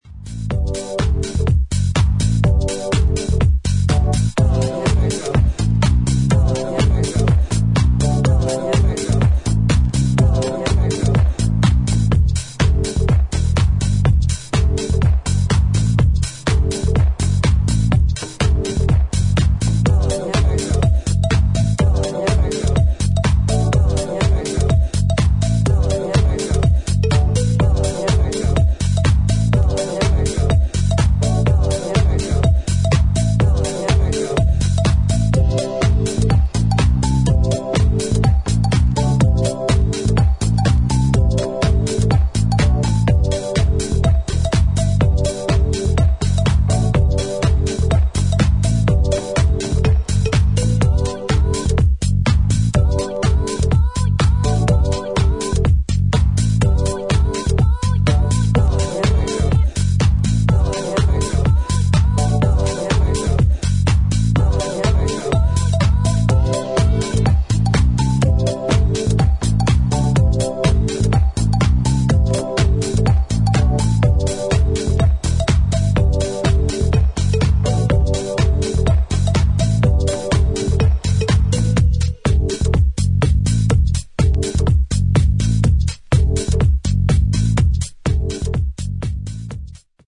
抜けの良いタイトな4つ打ちにダビーな生楽器の音色が絡む、ディープでファンキーなウェストコースト・スタイルハウス。